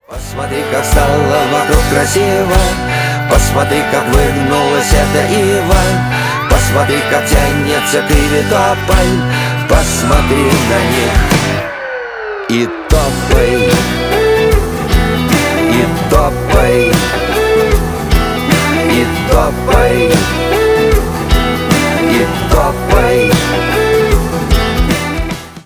Рок Металл
спокойные